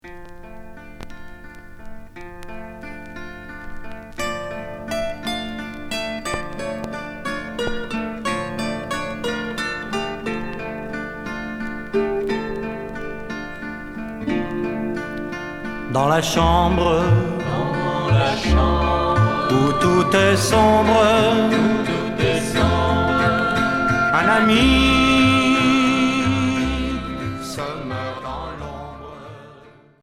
Folk Unique 45t retour à l'accueil